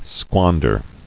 [squan·der]